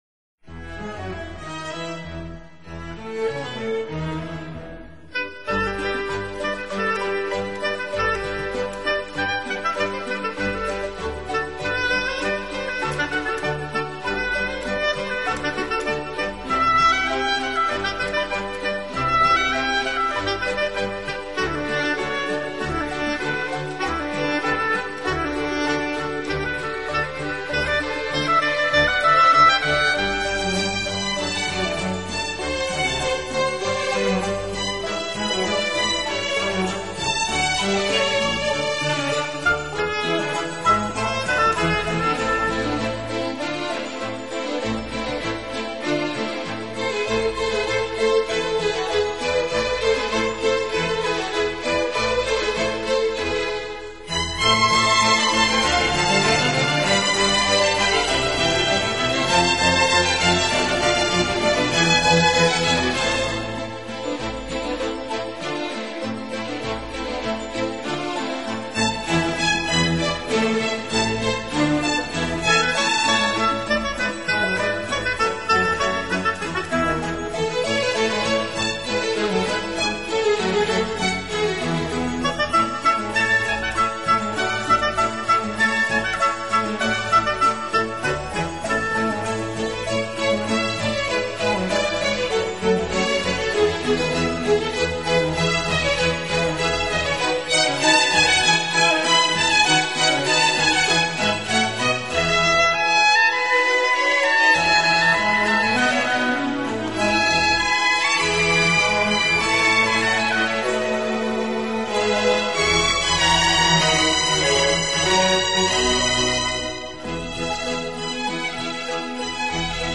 音乐类型：跨界融合 fusion